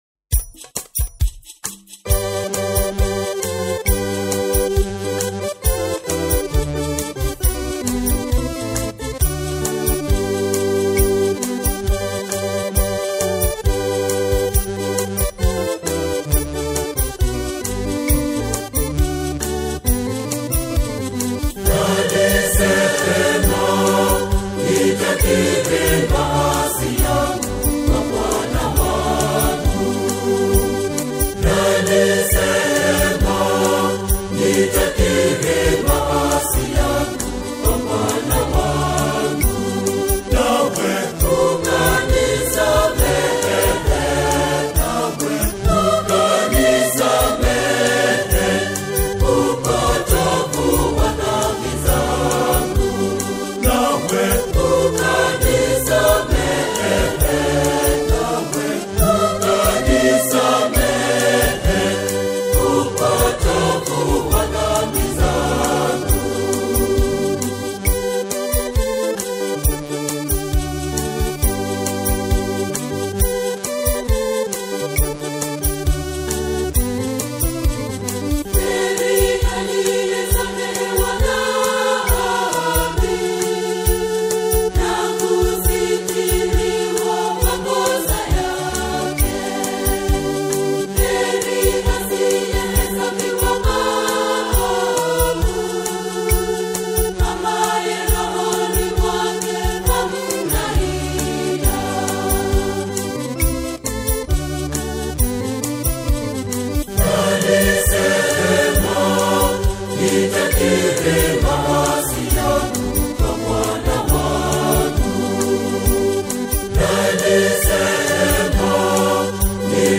a stirring and reflective liturgical single
authentic African melodies